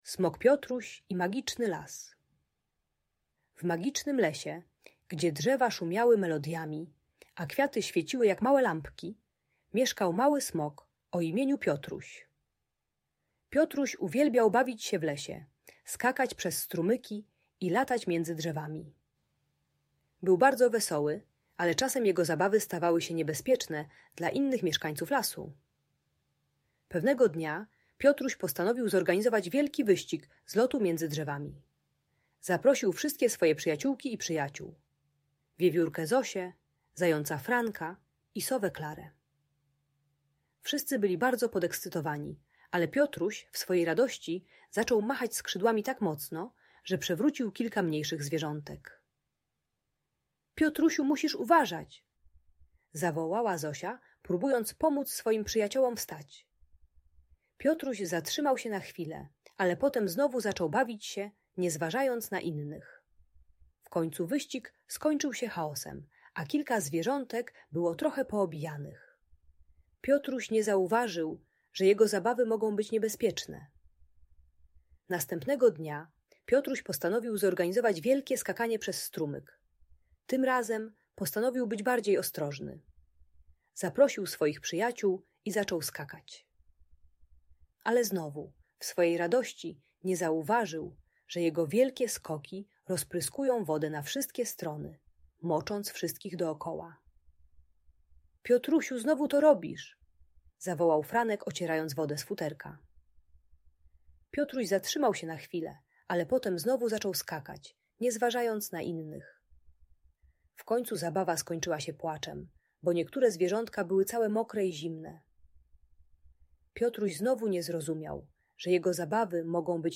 Przygody Smoka Piotrusia - Bunt i wybuchy złości | Audiobajka
Historia smoka Piotrusia uczy, jak kontrolować swoją energię i bawić się bezpiecznie, pytając innych o zgodę. Audiobajka o empatii i szacunku dla rówieśników.